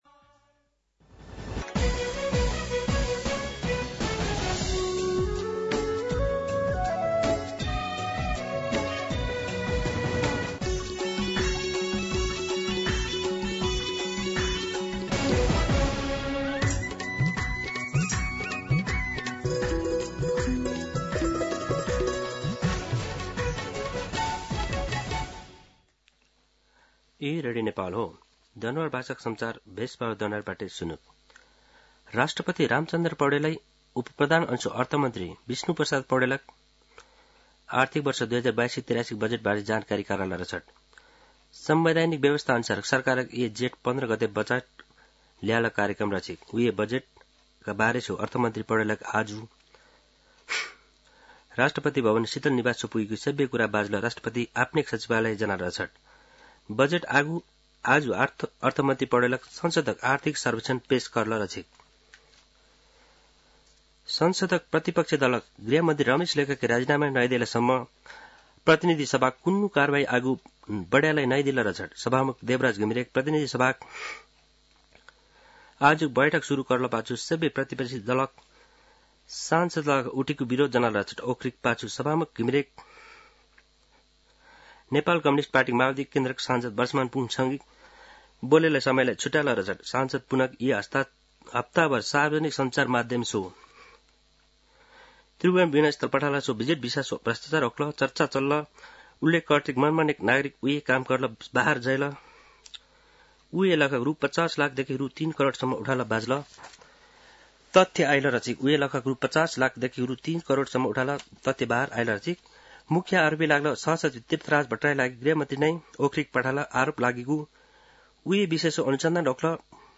दनुवार भाषामा समाचार : १३ जेठ , २०८२
Danuwar-News-13.mp3